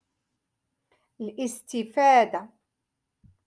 Moroccan Dialect-Rotation Five-Lesson Sixty Two